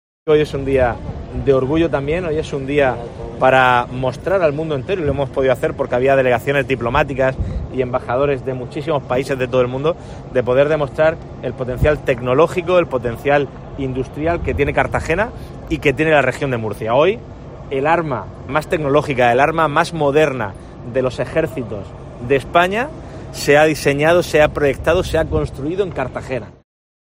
Fernando López Miras, presidente de la Región de Murcia